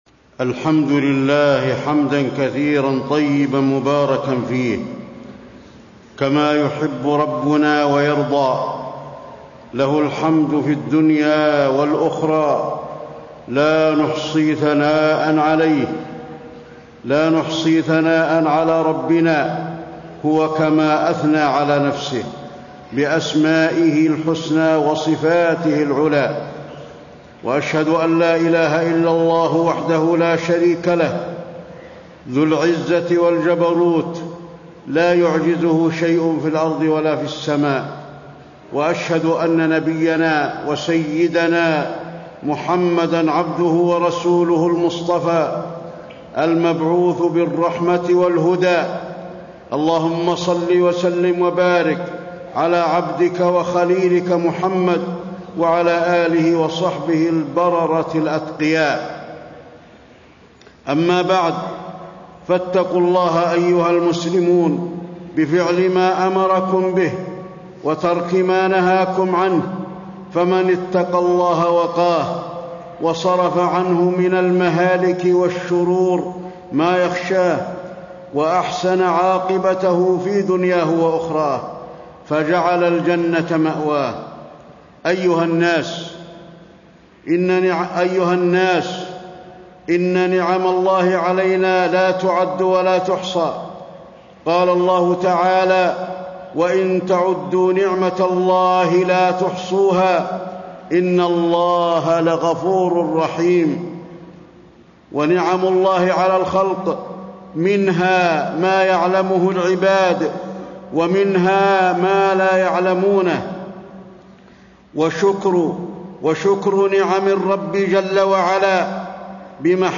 تاريخ النشر ١٢ ذو القعدة ١٤٣٣ هـ المكان: المسجد النبوي الشيخ: فضيلة الشيخ د. علي بن عبدالرحمن الحذيفي فضيلة الشيخ د. علي بن عبدالرحمن الحذيفي كيف نشكر الله تعالى على نعمه